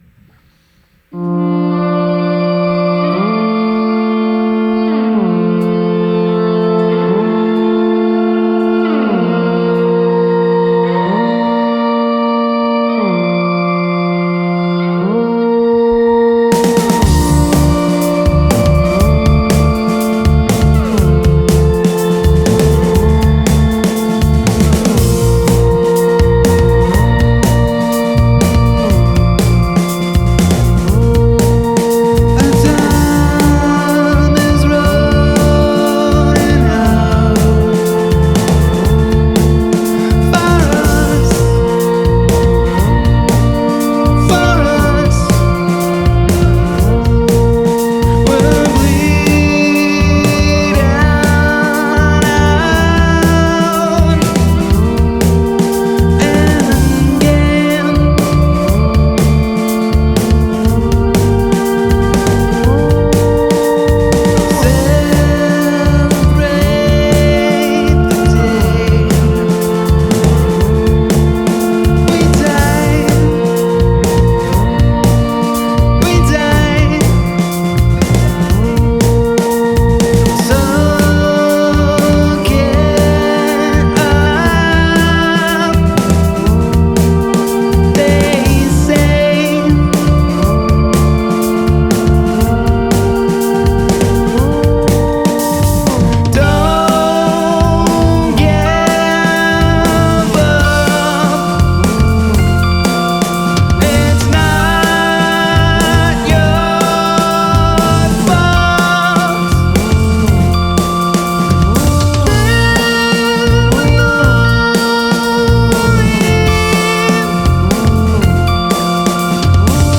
Recorded February 2021 during Lockdown - Band Studio Space.